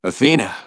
synthetic-wakewords
synthetic-wakewords / athena /ovos-tts-plugin-deepponies_Engineer_en.wav
ovos-tts-plugin-deepponies_Engineer_en.wav